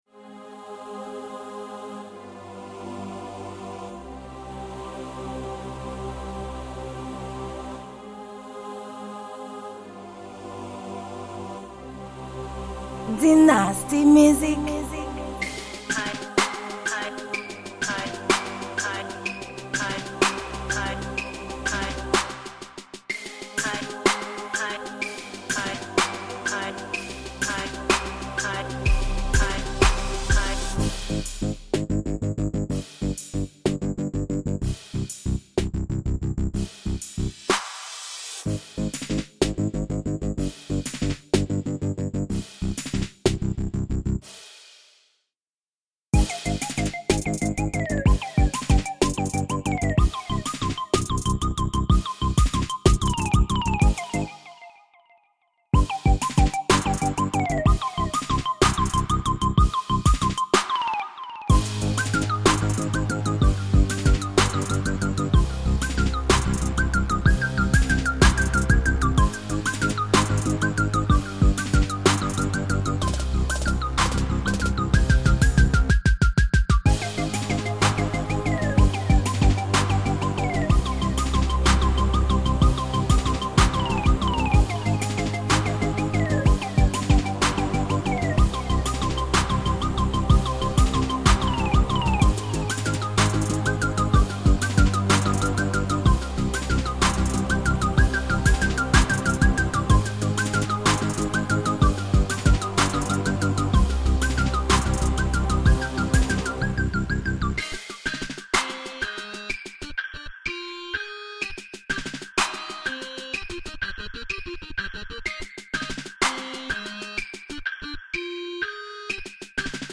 Genre: Beat.